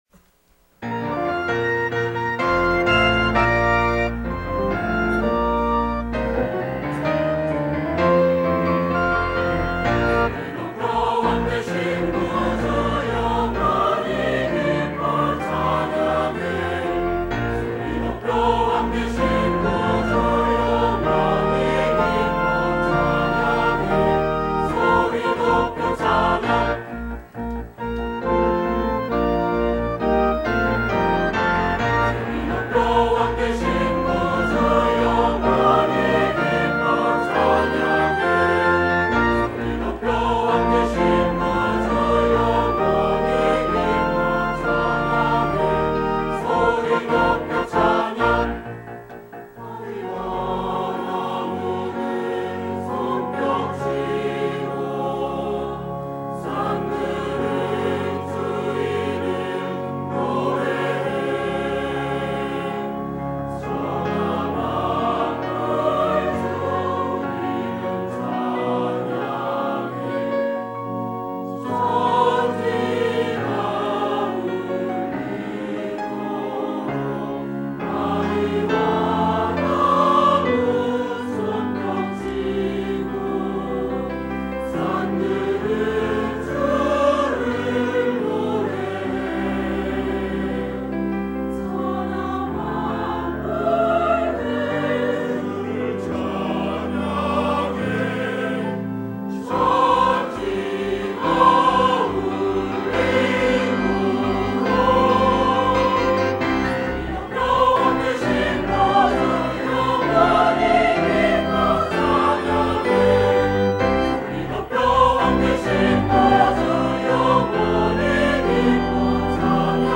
지휘 - 기뻐하리